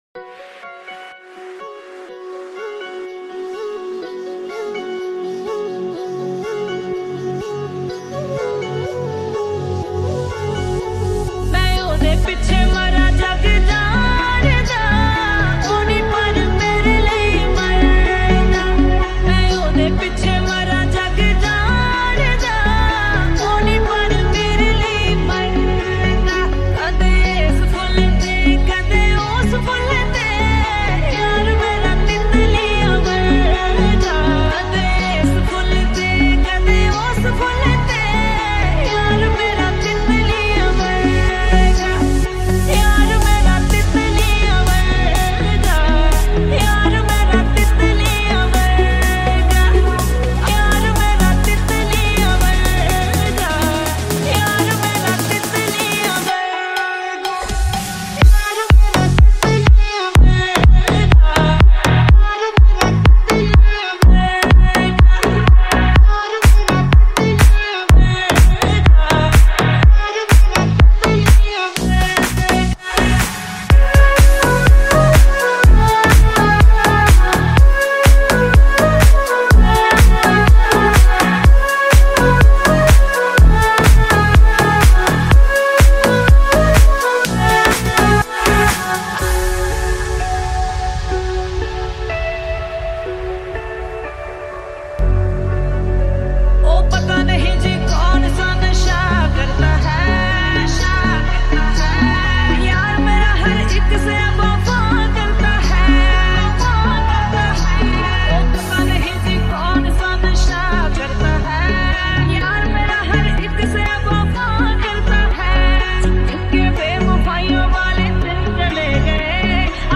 High quality Sri Lankan remix MP3 (3.6).